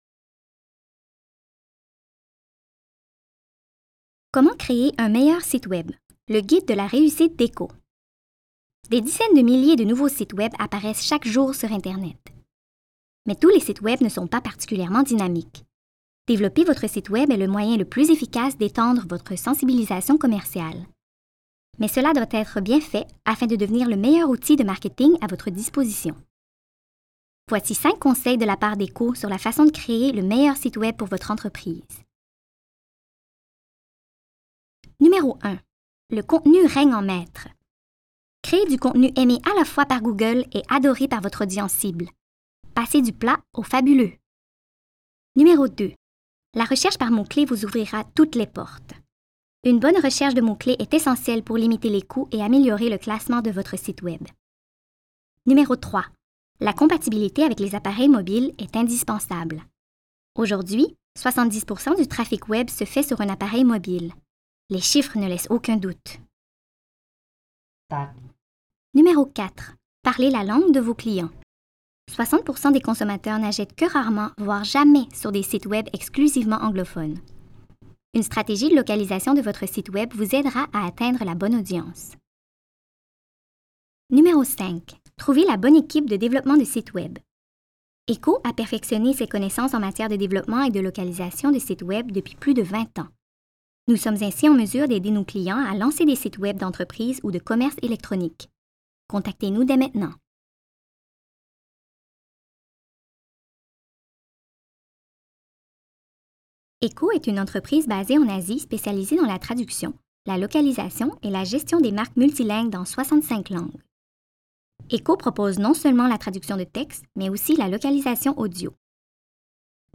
Voiceover Artists
EQHO provides multi-language solutions from its in-house recording facilities
French Female
COMMERCIAL